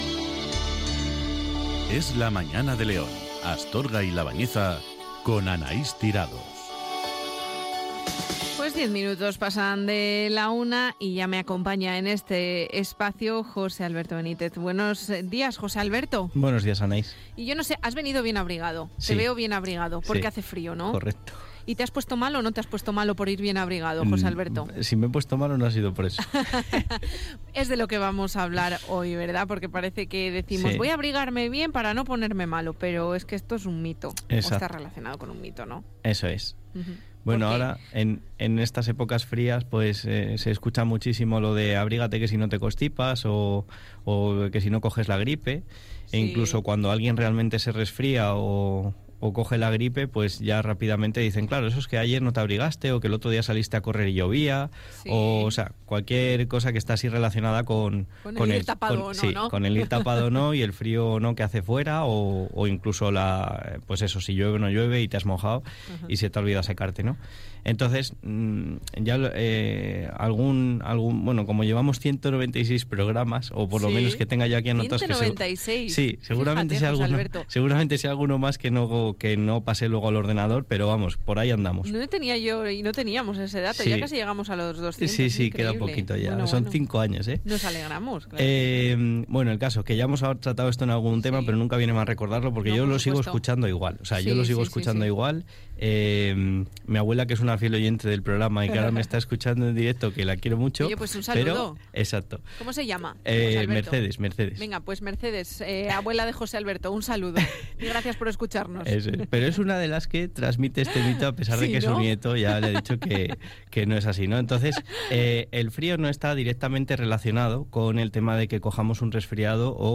Hoy os traigo el centésimo nonagésimo sexto programa de la sección que comenzamos en la radio local hace un tiempo y que hemos denominado Es Saludable, en el programa Es la Mañana de León, Astorga y La Bañeza en EsRadio.